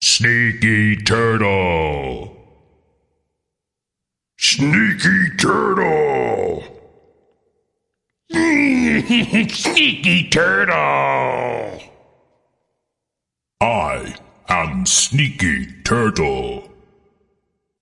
标签： fart farts male voice voiceover
声道立体声